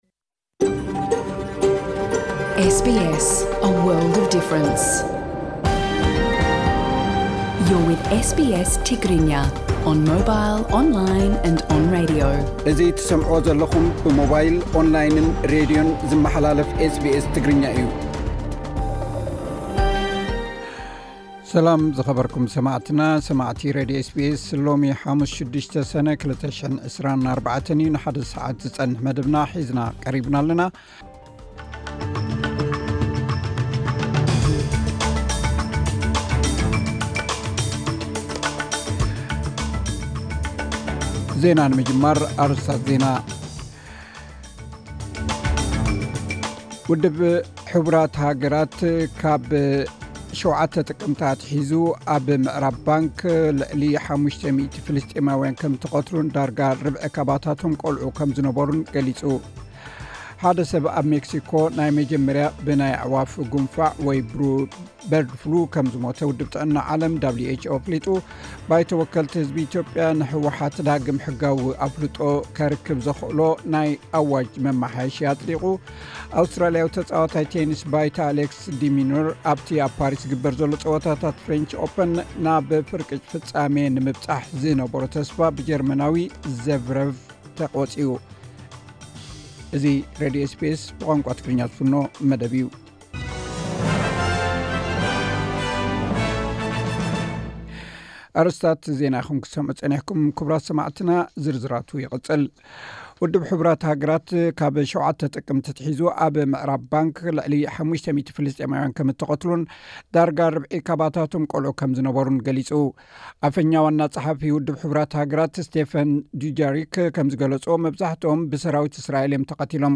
ዕለታዊ ዜና ኤስ ቢ ኤስ ትግርኛ (06 ሰነ 2024)